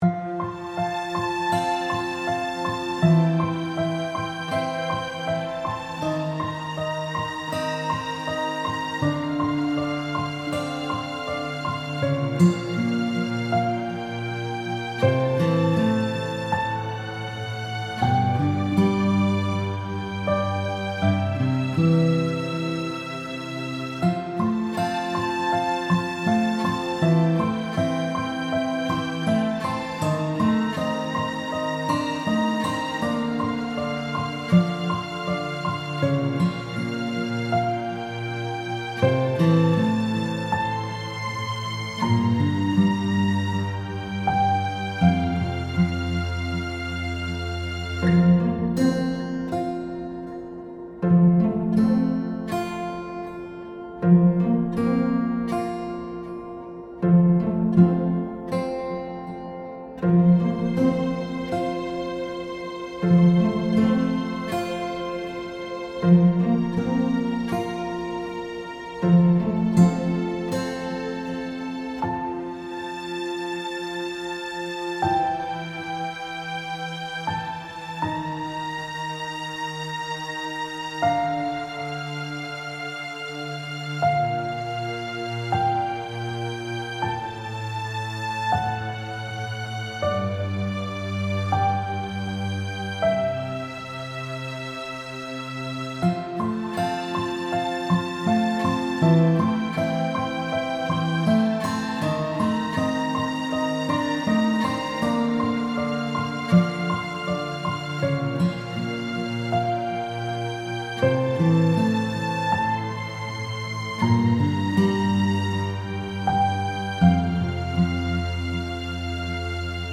柔らかい風が吹いてそうな優しいBGMです。